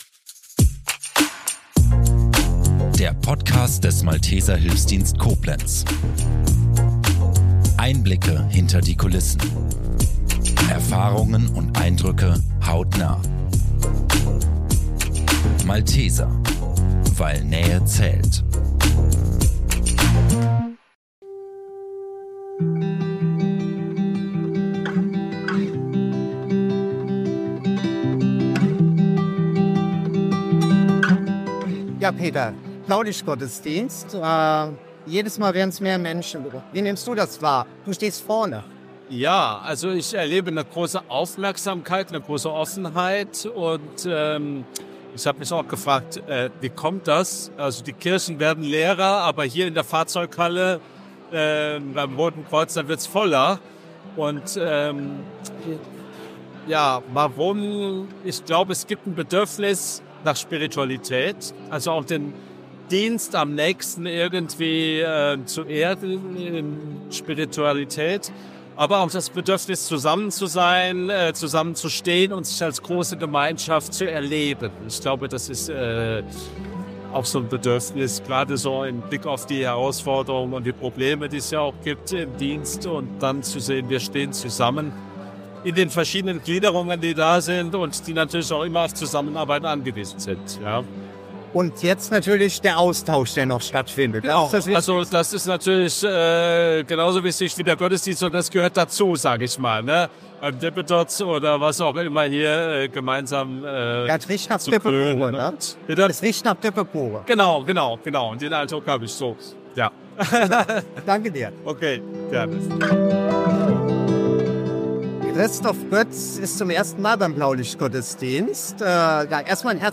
Der Blaulichtgottesdienst 2025 stand unter dem Leitthema „Die Würde des Menschen“ – ein Thema, das im Einsatzalltag von Rettungsdiensten, Feuerwehr, THW und Polizei eine besondere Bedeutung hat. Die Andacht wurde ökumenisch gestaltet und musikalisch begleitet.